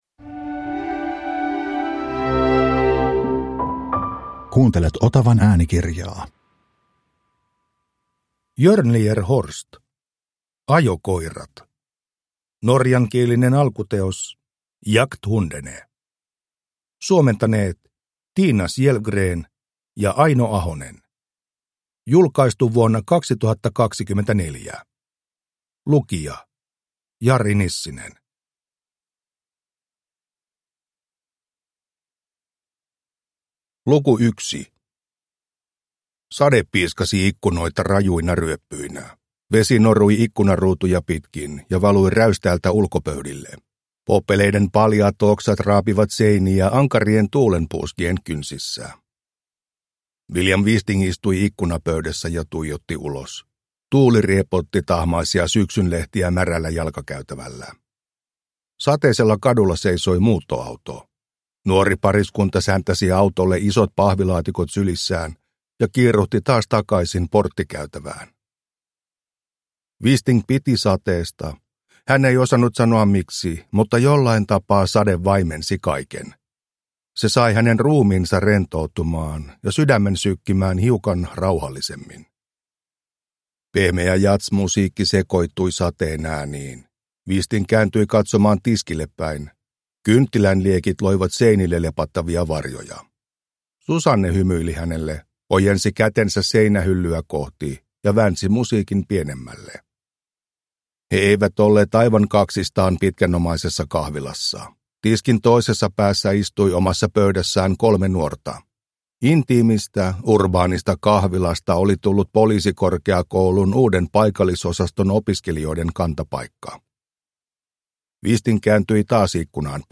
Ajokoirat – Ljudbok